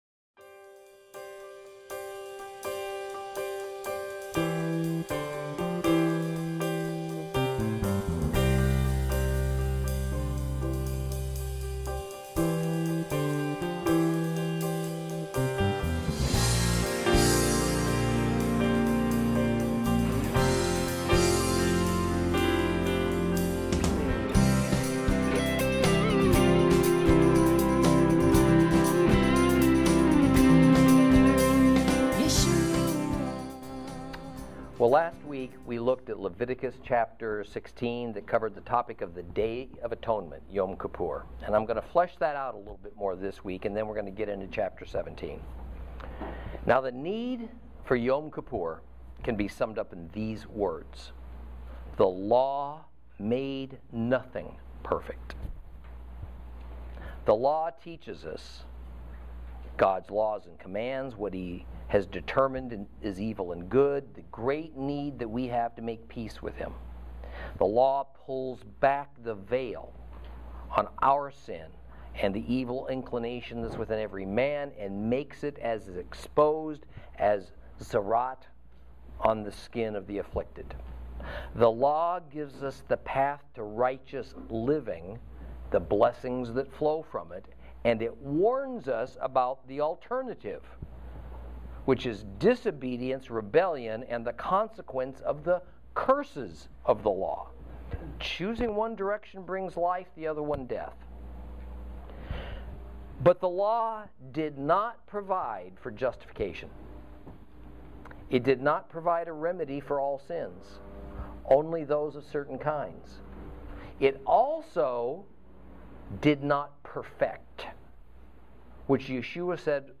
Lesson 24 Ch16 Ch17 - Torah Class